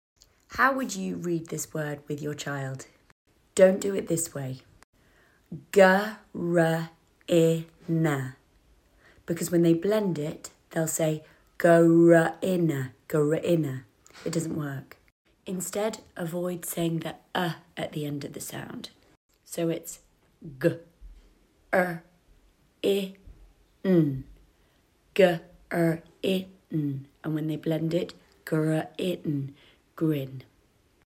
Avoid saying the “uh” at the end of the sound.